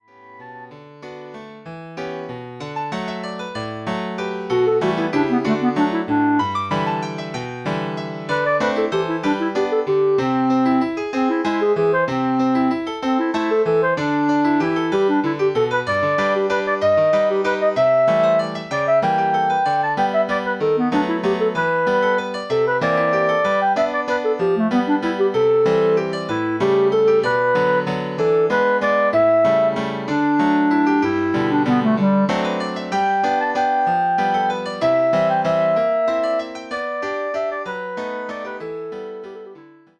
All works are written for clarinet and piano.